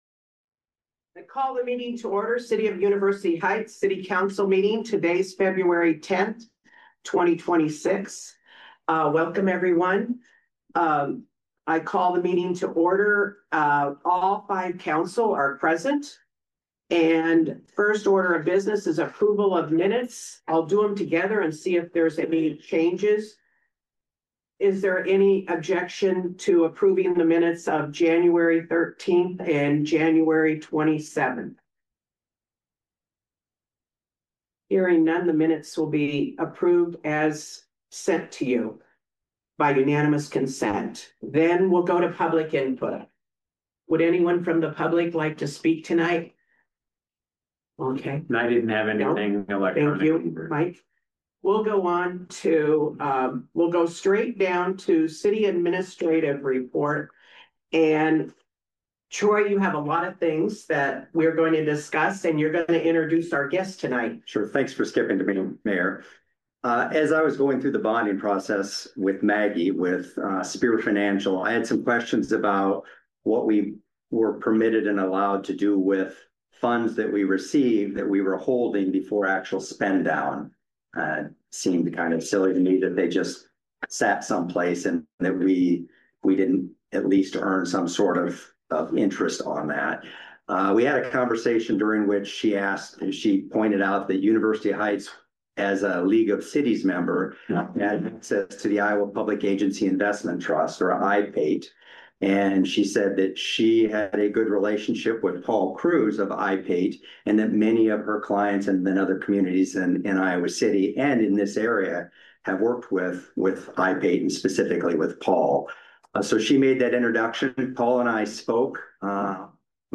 The monthly meeting of the University Heights City Council.